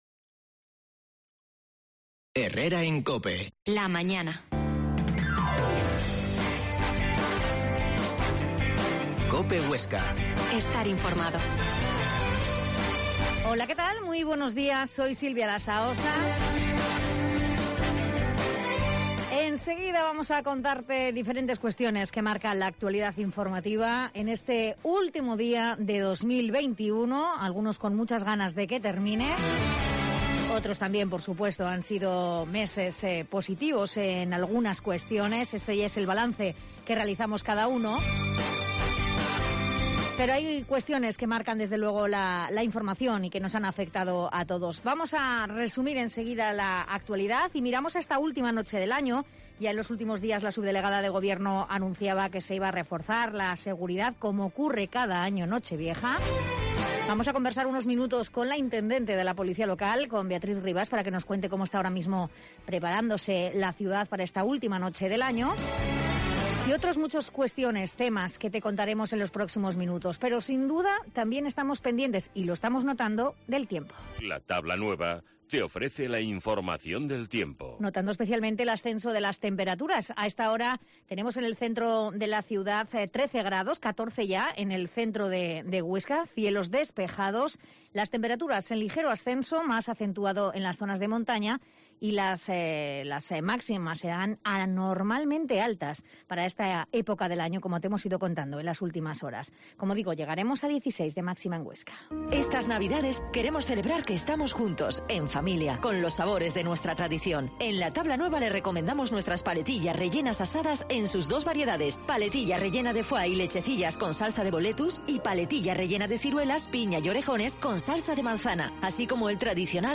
La Mañana en COPE Huesca - Informativo local Herrera en Cope Huesca 12,50h. Entrevista